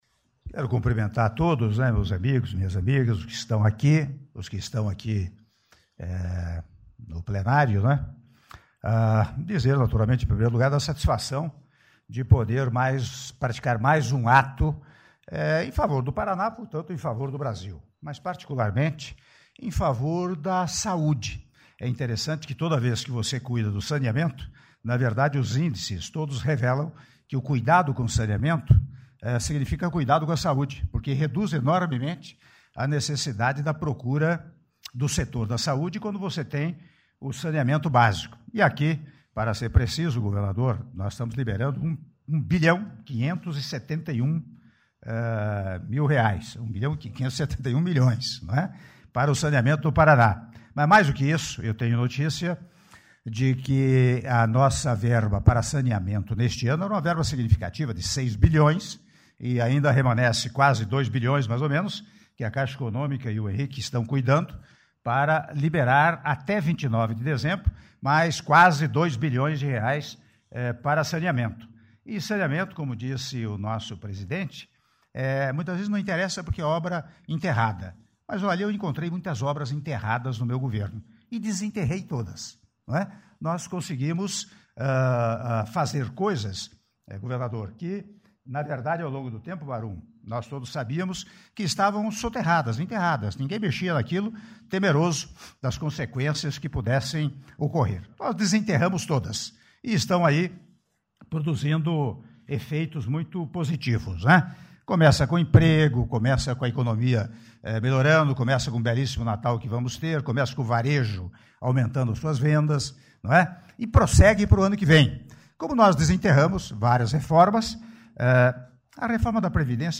Áudio do discurso do Presidente da República, Michel Temer, durante assinatura de Contratos - Programa Saneamento para Todos (Sanepar) - Palácio do Planalto - (05min51s)